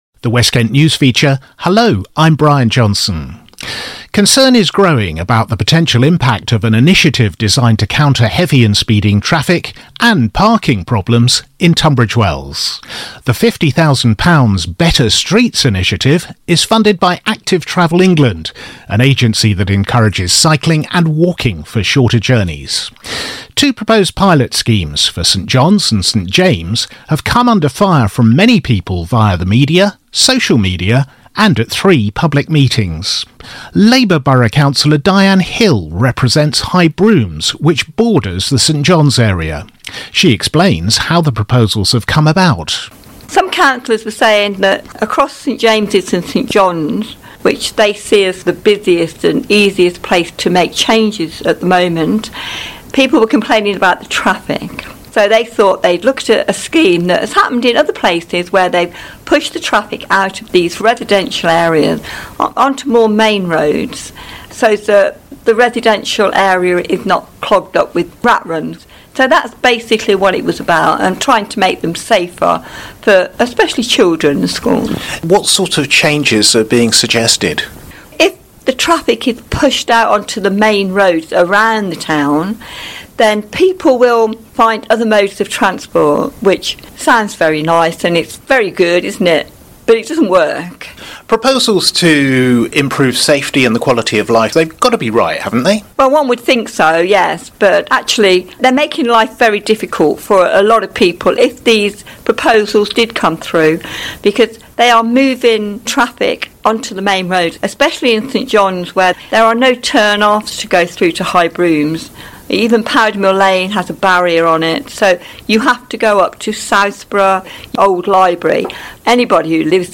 You can listen to Cllr Dianne Hill's full interview